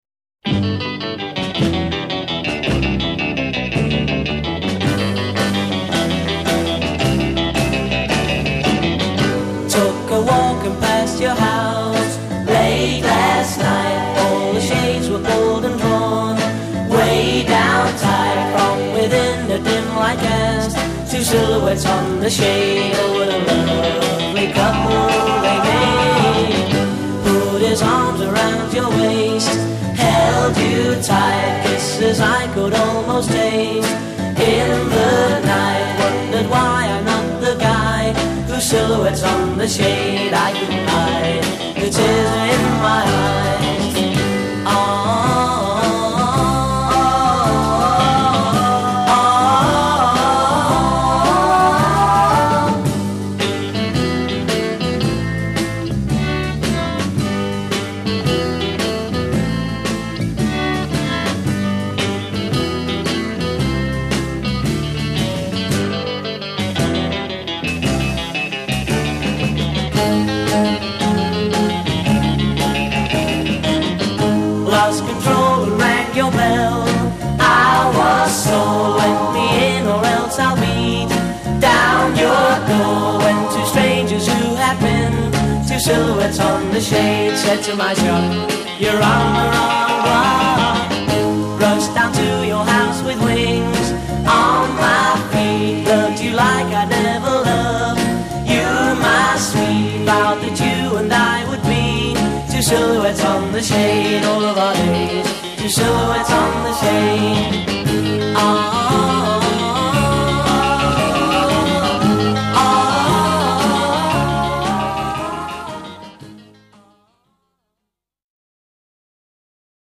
vocals & keyboards
A Verse 0: 16 solo vocal w/ harmony at phrase ends a
A Verse : 16 guitar solo
[A] Intro/fill : 16+ repeat and fade c